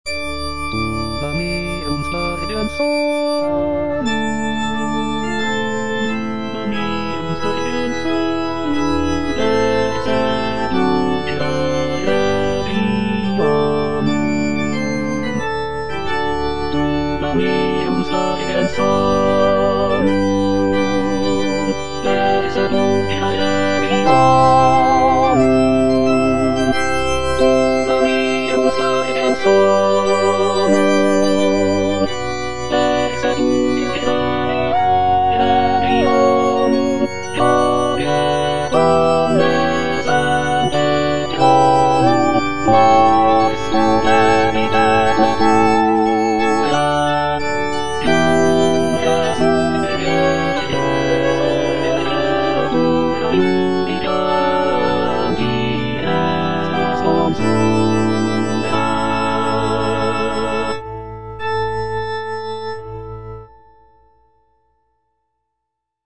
(All voices)
is a sacred choral work rooted in his Christian faith.